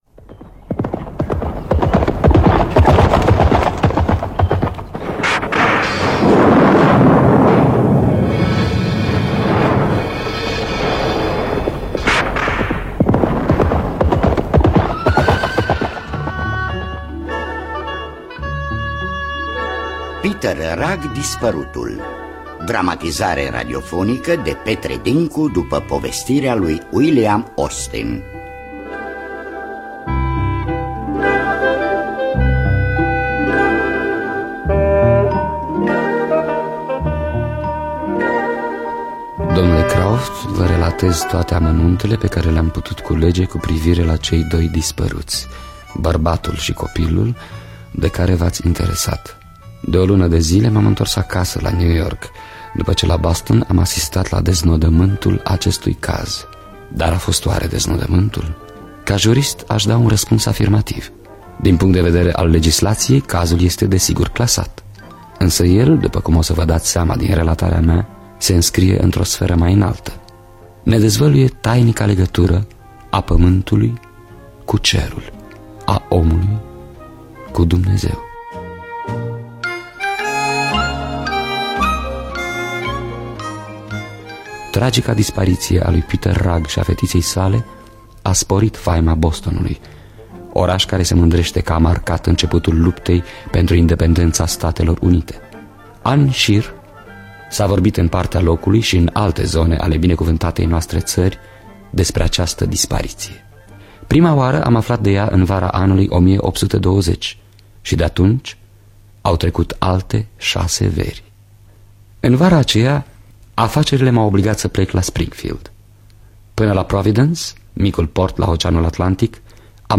William Austin – Peter Rugg Disparutul (1994) – Teatru Radiofonic Online